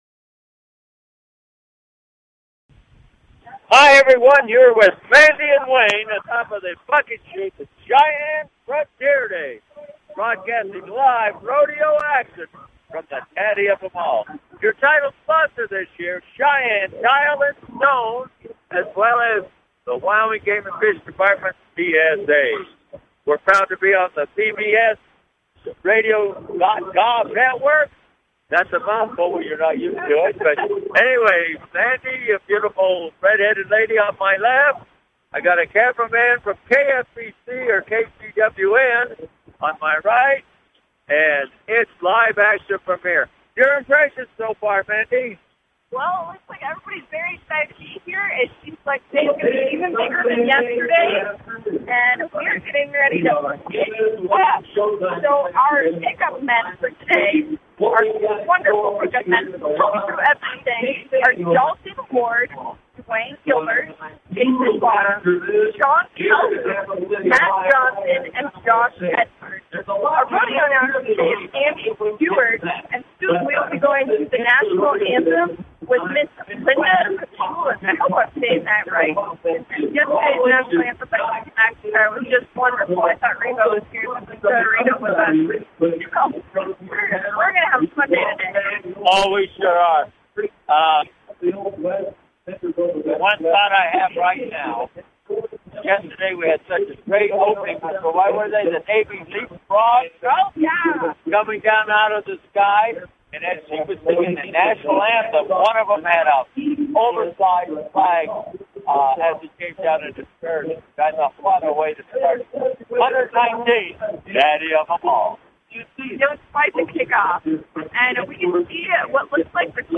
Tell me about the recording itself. Live play by play rodeo experiences!